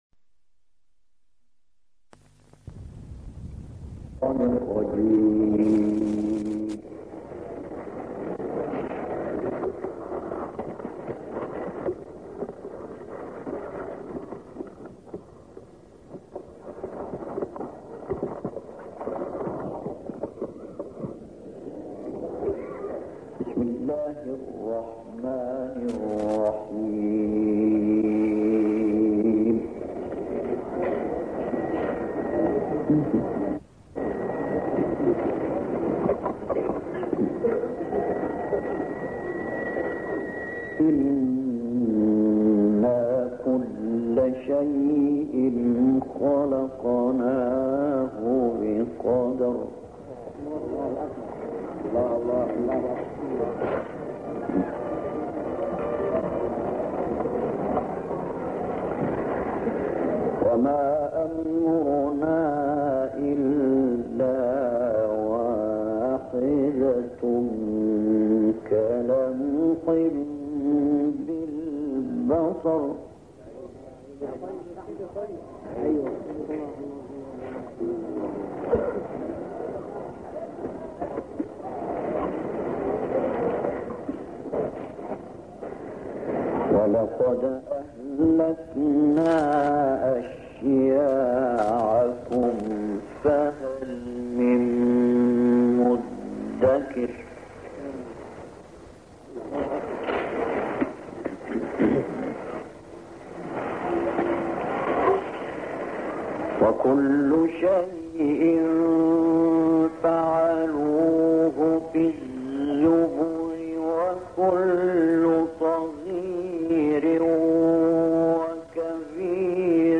تلاوت سور قمر و الرحمن